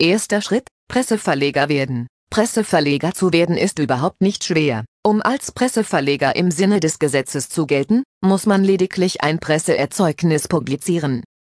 Heruntergeladen und installiert bietet „Toau“ nicht viel mehr als eine Eingabe-Box, die mit beliebigen Texten gefüttert werden kann und diese anschließend in eine AIFF-Audiodatei umwandelt, die sich an iTunes und somit auch ans iPhone übergeben lässt.
Hat man sich an Apples Vorlesestimme „Anna“ gewöhnt, ein durchaus brauchbares Features und eine Funktion die vor allem praktisch für längere Texte ist, die nur in Schriftform vorliegen, auf dem Pendler-Weg zur Uni oder zum Arbeitsplatz aber noch mal überflogen werden müssen.